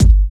23 KICK.wav